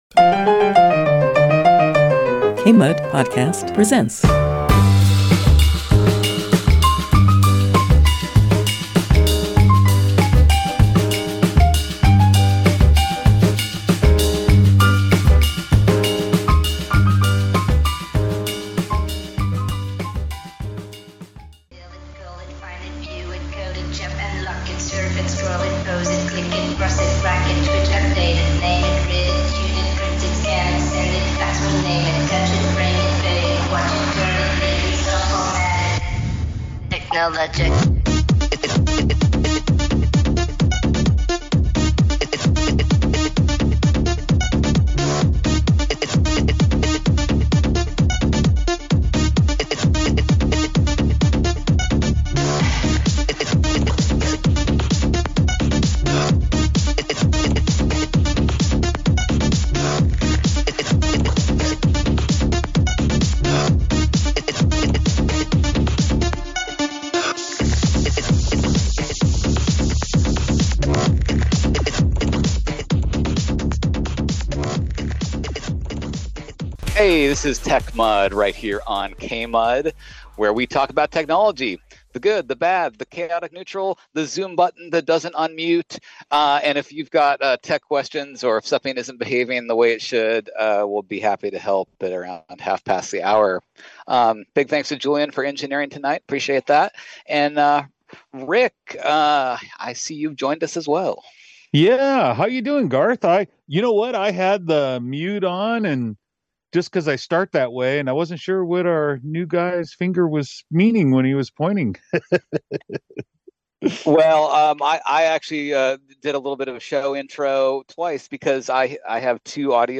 The discussion is shaped by live caller questions about monitoring, data tracking, and practical ways to protect personal information.